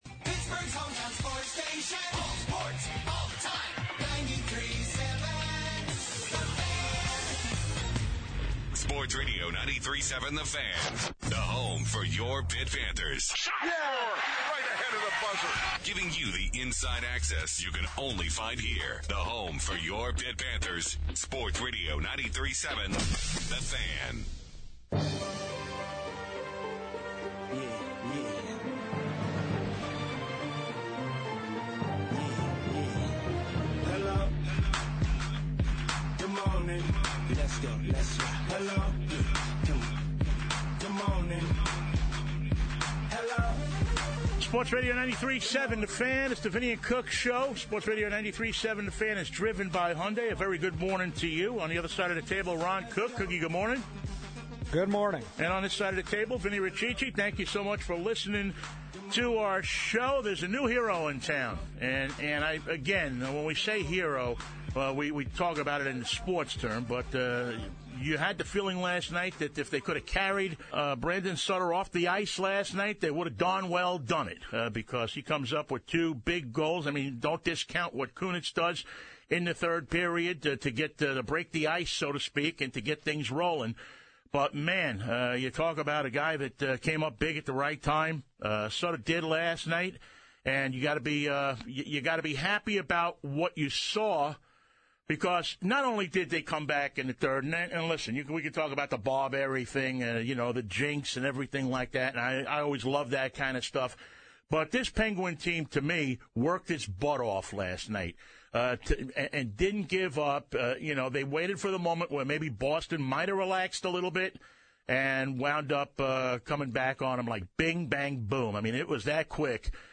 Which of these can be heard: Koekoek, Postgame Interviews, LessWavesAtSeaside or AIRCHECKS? AIRCHECKS